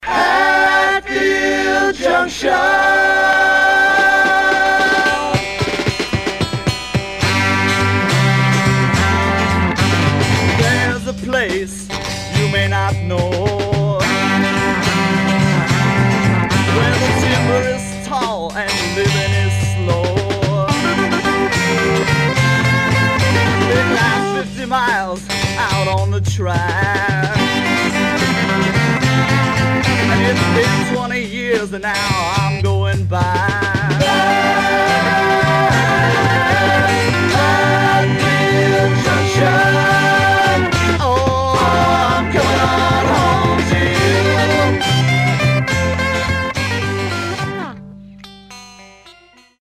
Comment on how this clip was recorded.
Some surface noise/wear Mono